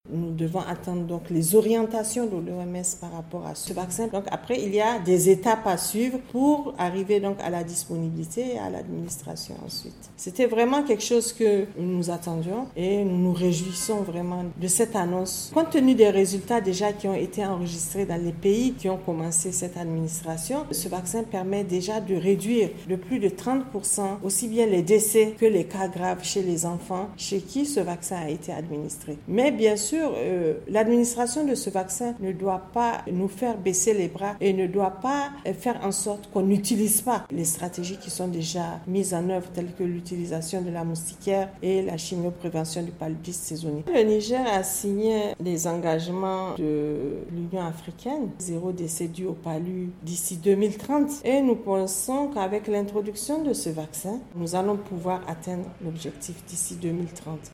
Iinterview